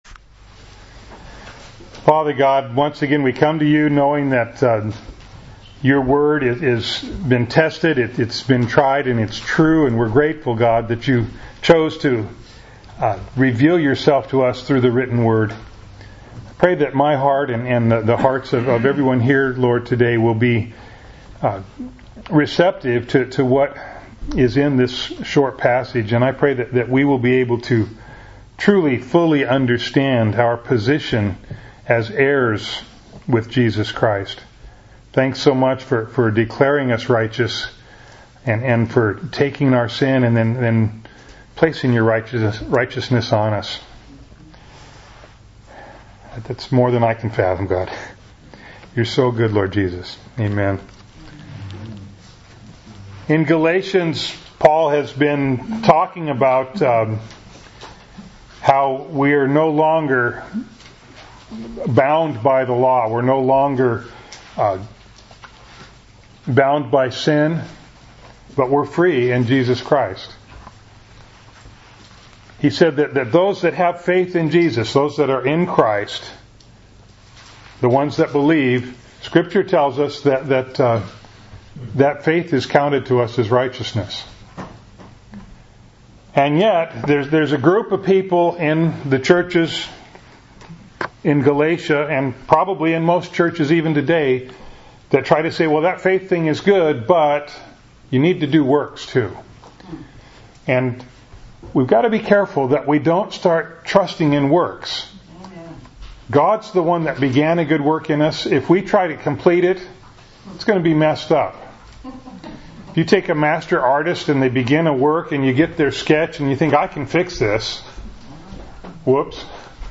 Bible Text: Galatians 4:1-7 | Preacher